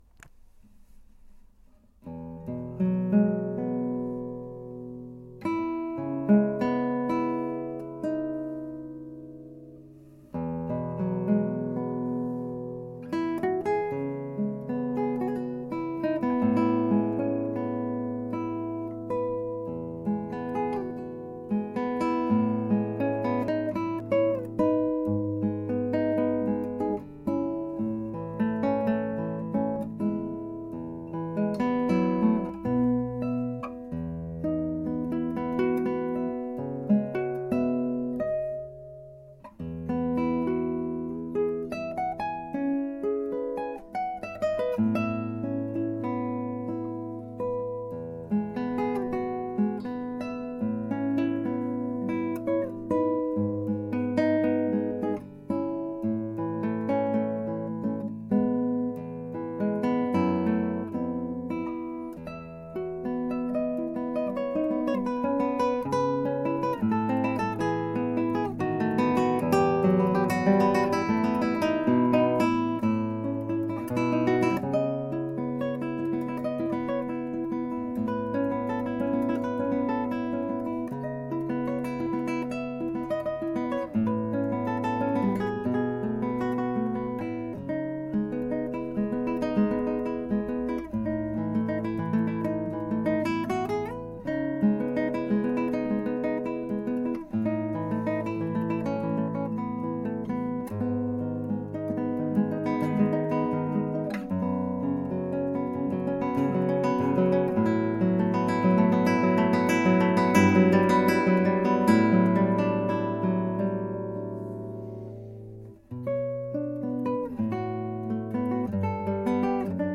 Chitarra Classica